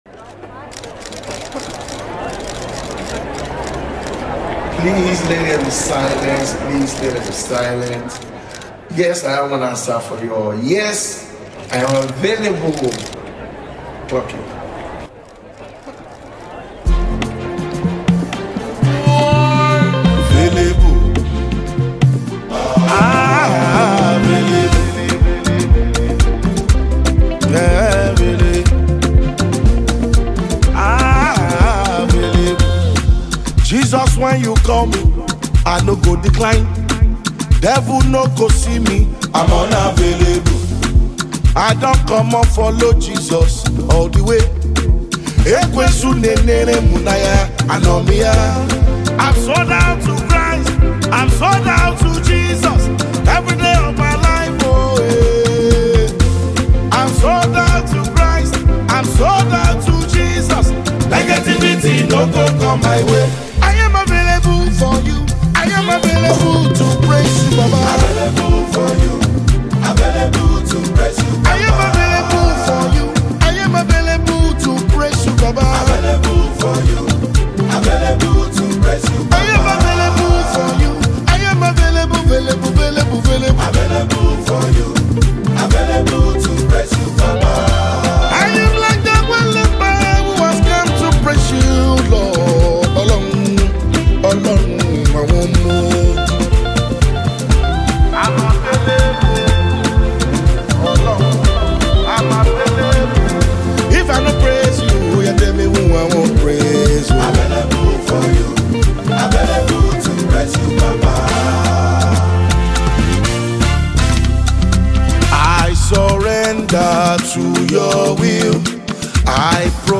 gospel
With passionate vocals and uplifting melodies